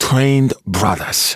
召唤少林寺武僧攻击敌人，语音多与武术有关并带有严重的口音。